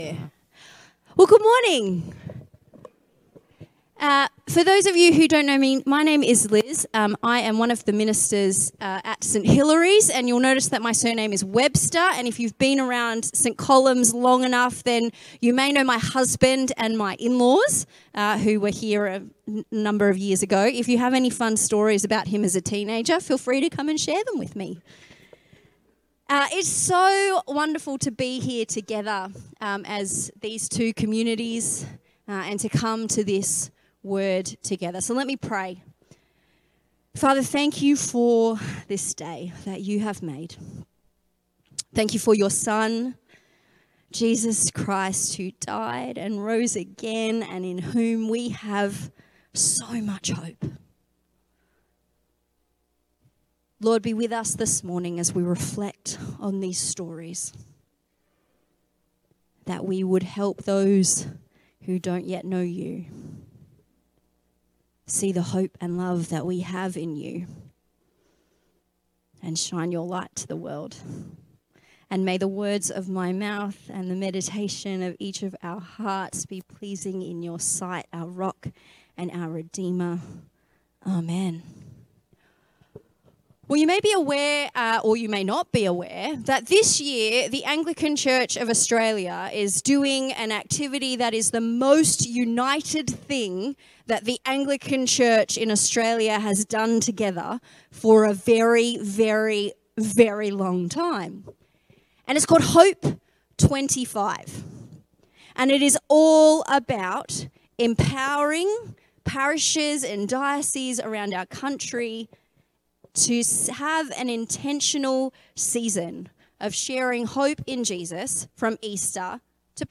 Preached at St Columb's joint morning service with St Hilary's.